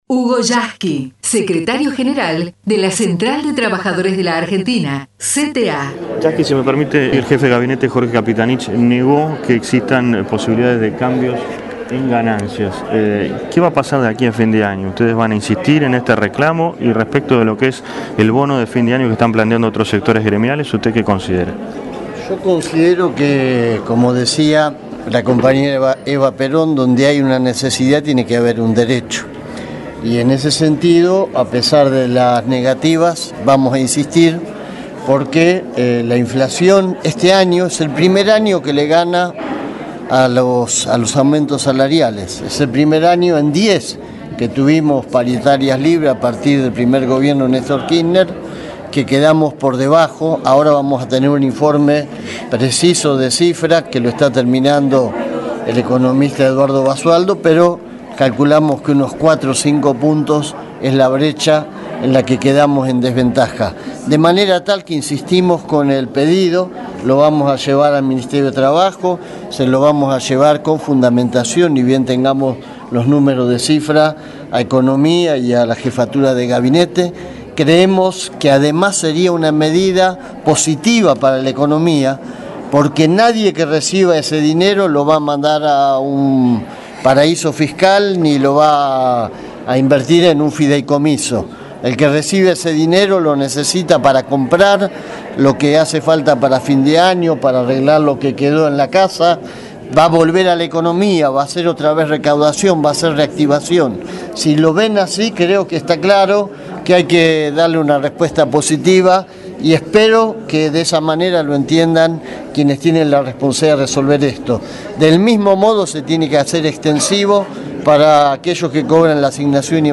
HUGO YASKY (en rueda de prensa) pago de ganancias y bono de fin de año